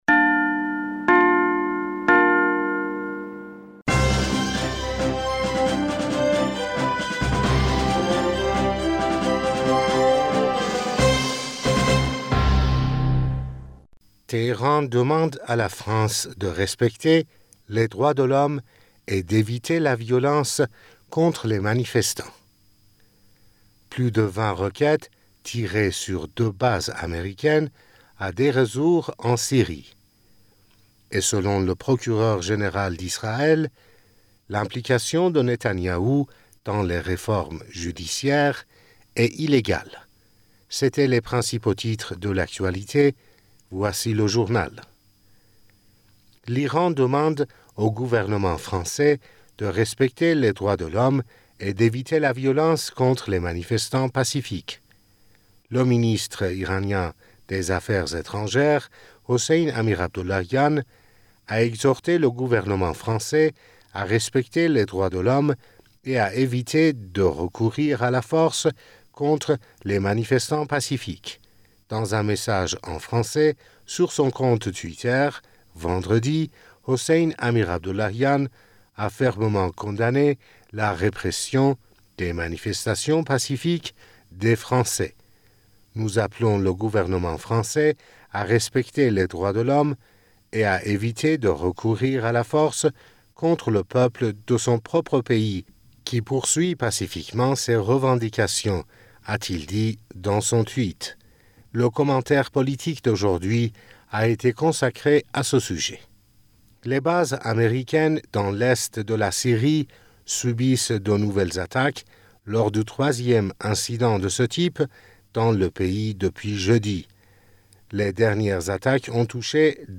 Bulletin d'information du 25 Mars